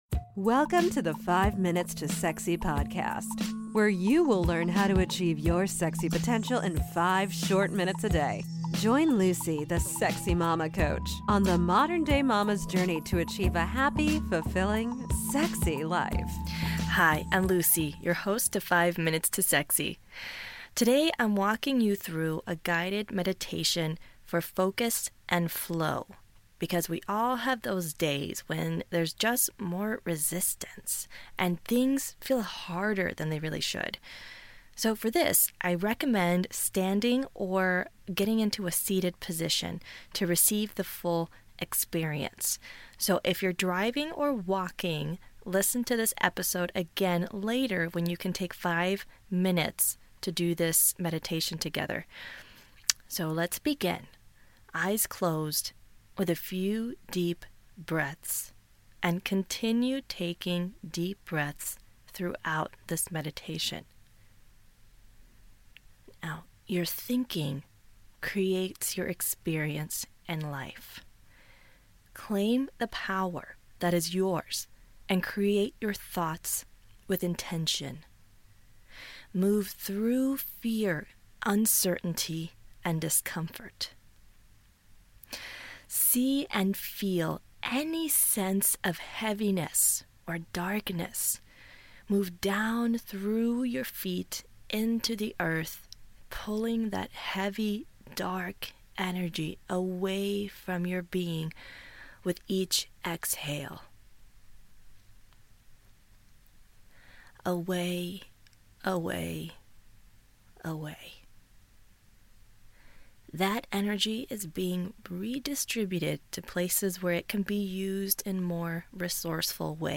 Focus & Flow: Guided Meditation